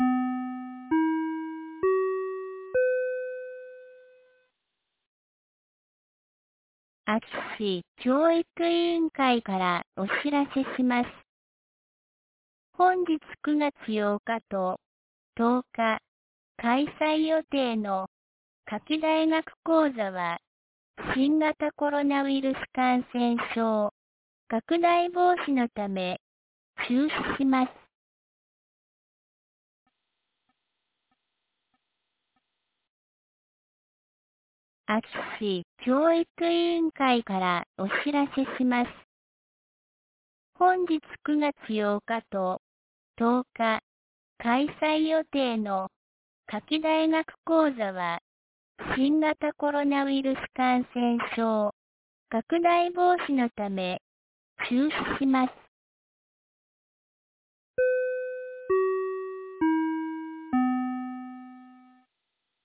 2022年09月08日 17時11分に、安芸市より全地区へ放送がありました。